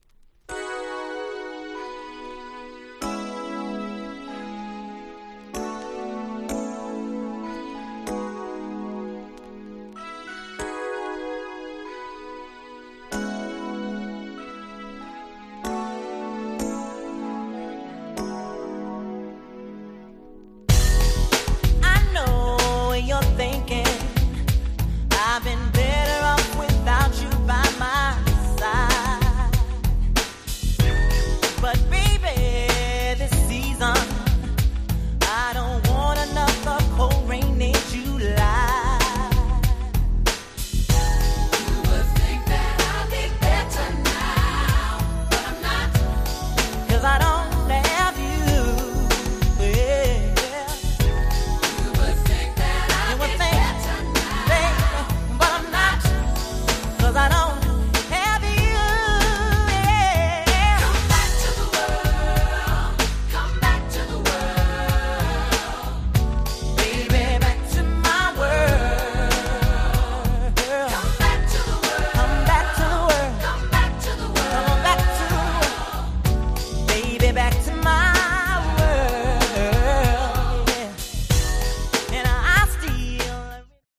例えるなら日曜日の昼下がり系！！
90's